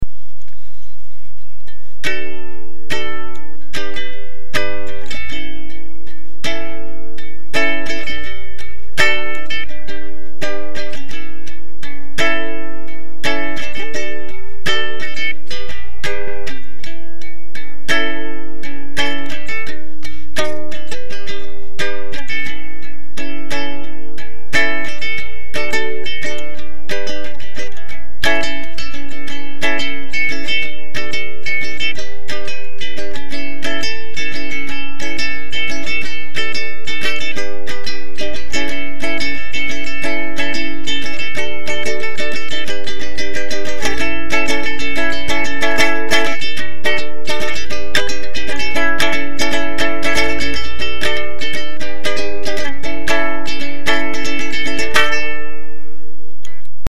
puis a nouveau un uke. manche acajou et le reste en wengé avec une finition ultra sobre que j’aime beaucoup et en gardant la forme de la martin backpacker. la taille et le bois ont donné un très bon son, bien claquant.